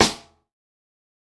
TC3Snare7.wav